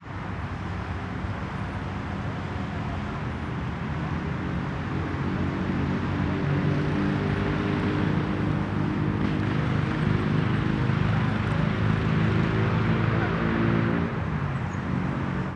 CSC-18-077-GV - Moto Acelerando e Passando no Eixinho da Asa Norte.wav